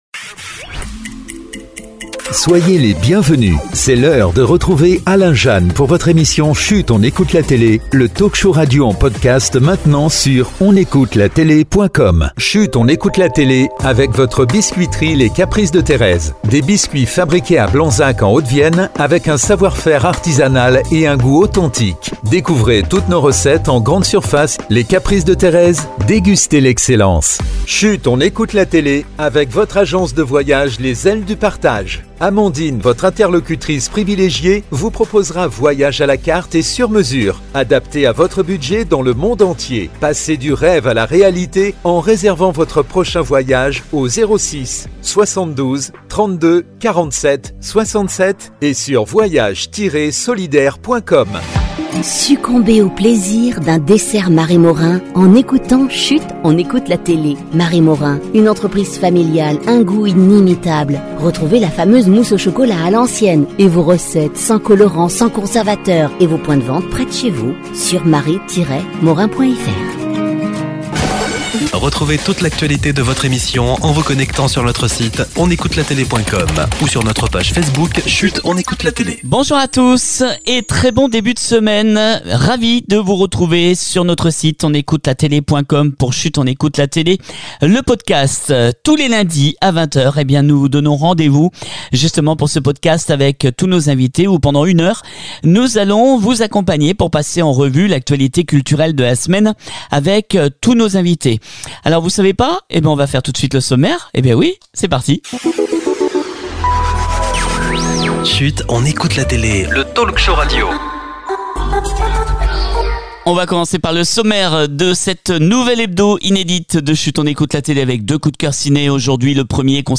On se retrouve ce lundi 14 Avril 2025 pour un nouveau rendez vous de Chut on écoute la télé avec de nombreux invités, on parle de